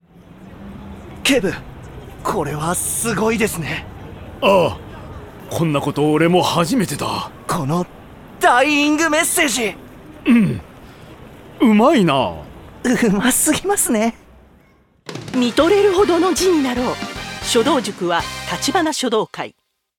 ことわざやお腹の音を使って、手軽に本格明太子が味わえることを伝えています。